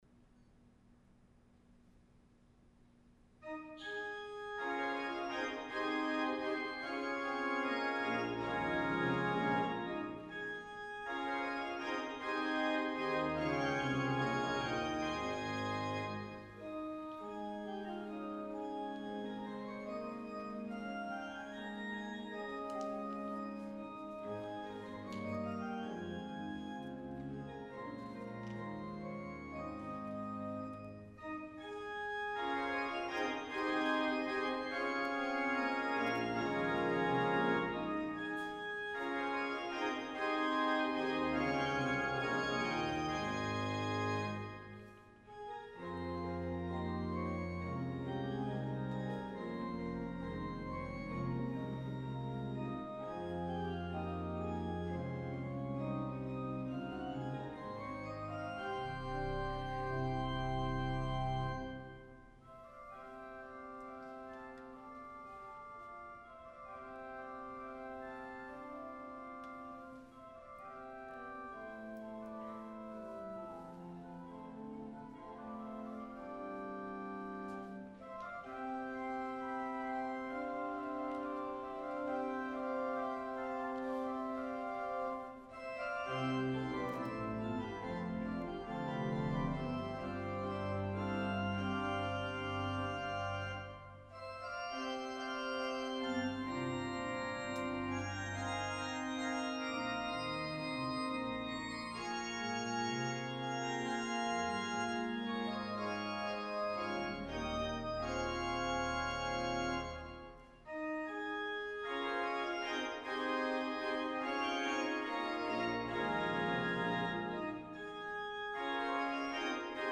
Audio only for Sunday Worship 9-27-20